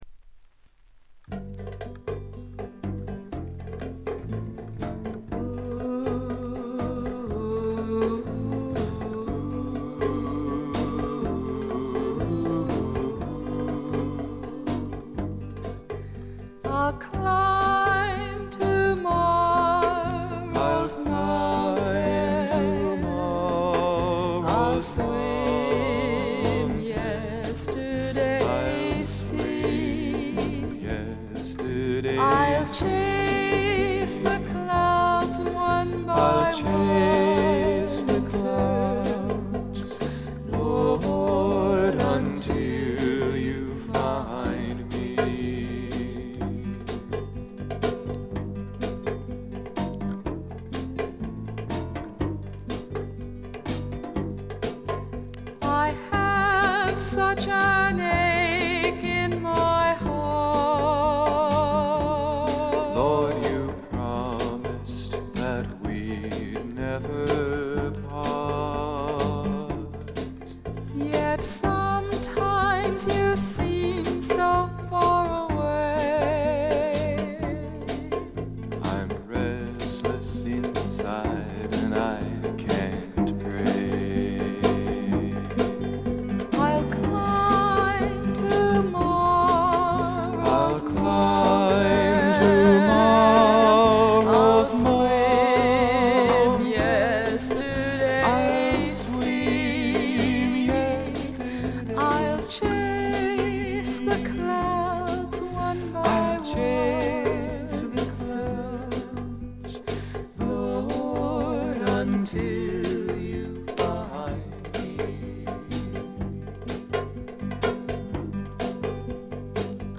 プレスのためかバックグラウンドノイズ、チリプチが出ます。
知る人ぞ知る自主制作ポップ・フォークの快作です。
試聴曲は現品からの取り込み音源です。